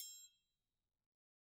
Triangle3-HitM_v1_rr2_Sum.wav